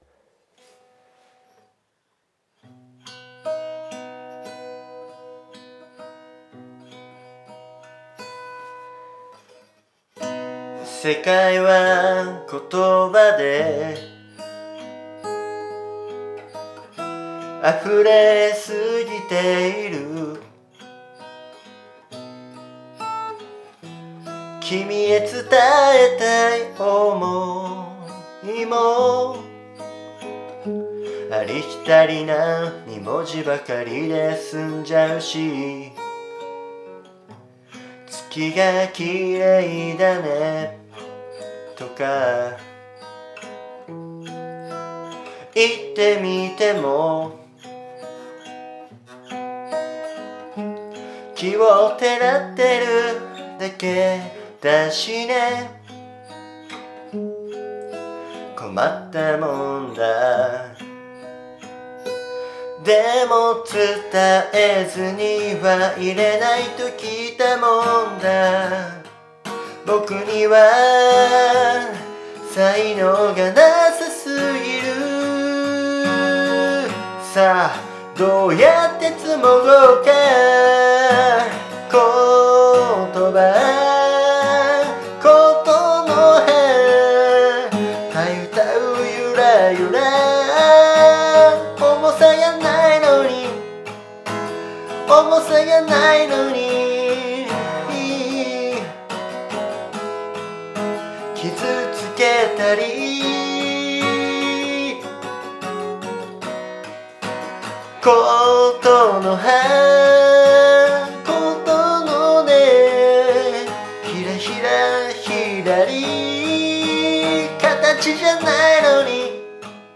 だからこそメロディーは、まさに語ってるような、言葉ってるような感じにしたいなと思ってつけてみました。
家で歌ってるから少し微妙な部分あったかもだけど、ごめんね。